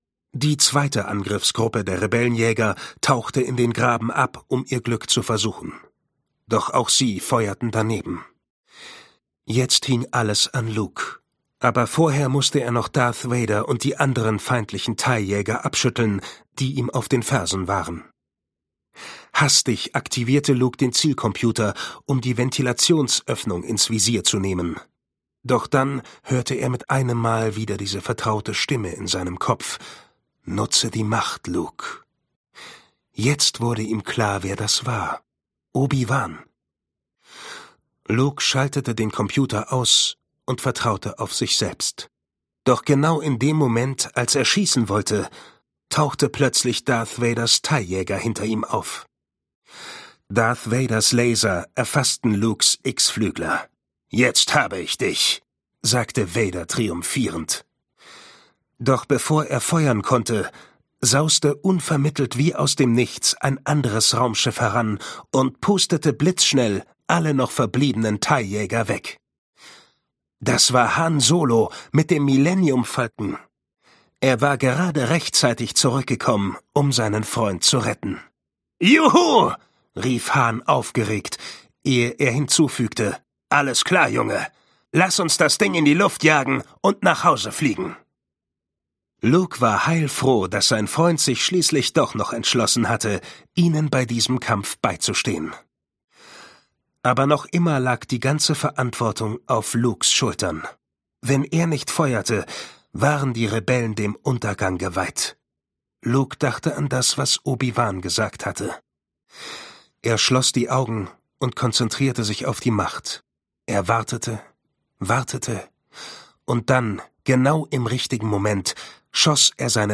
Hörbuch
Ungekürzte Lesung